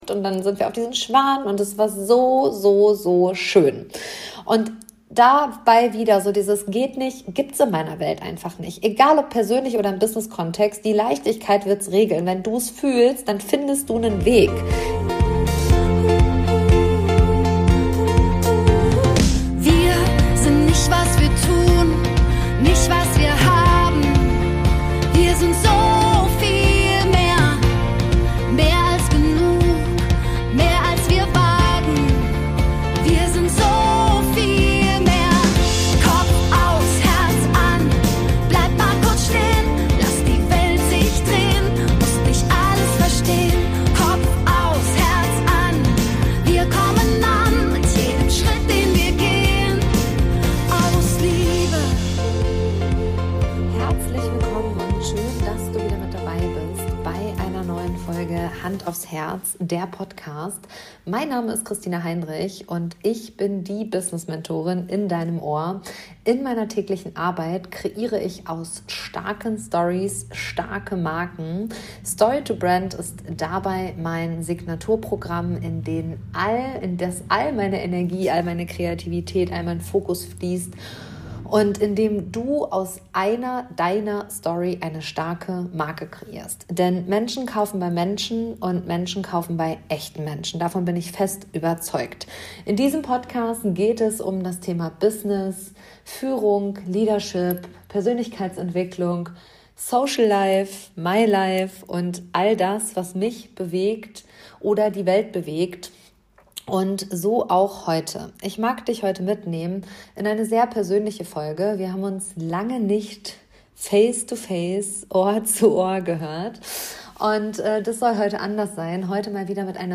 Diese Folge ist laut und leise gleichzeitig.